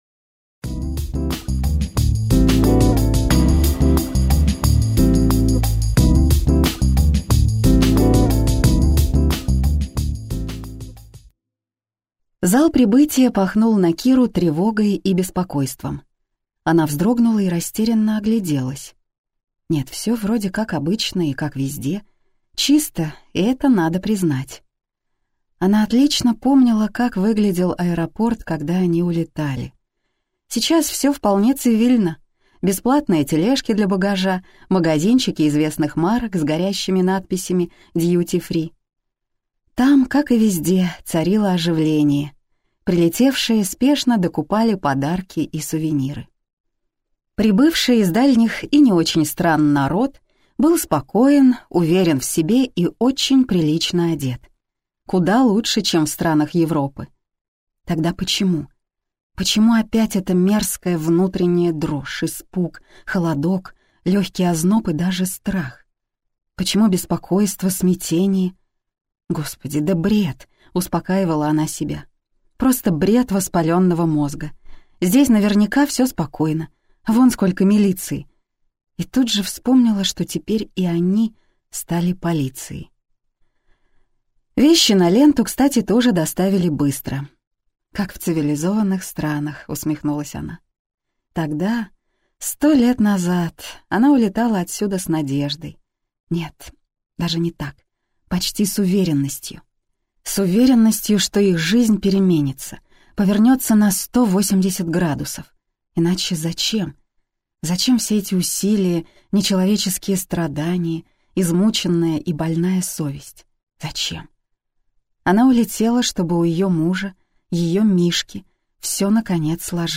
Аудиокнига И все мы будем счастливы - купить, скачать и слушать онлайн | КнигоПоиск